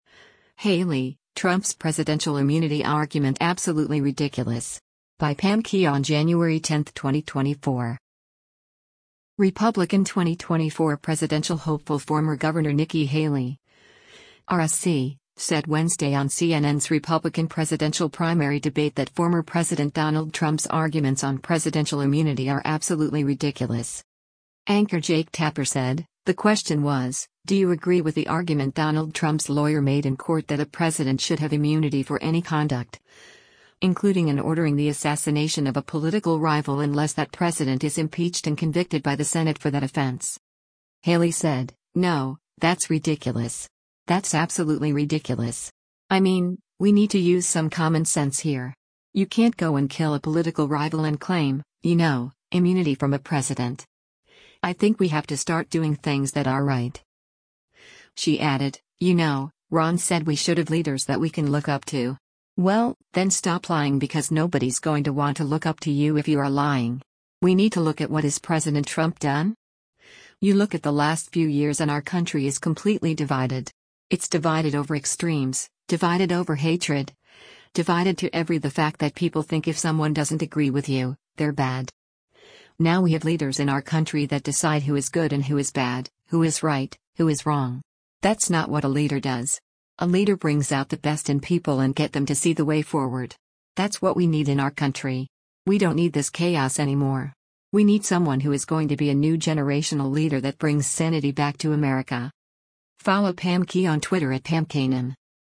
Republican 2024 presidential hopeful former Gov. Nikki Haley (R-SC) said Wednesday on CNN’s Republican presidential primary debate that former President Donald Trump’s arguments on presidential immunity are “absolutely ridiculous.”